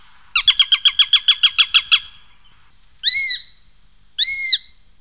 Epervier d'Europe
Accipiter nisus
epervier.mp3